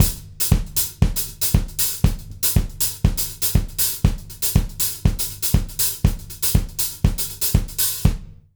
120ZOUK 07-L.wav